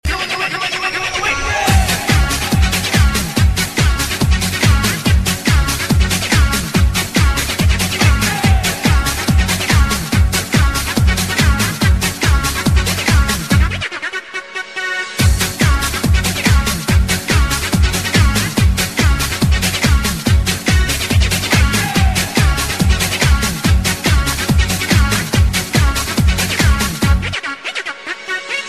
• Качество: 128, Stereo
ритмичные
энергичные
веселый, ритмичный рингтон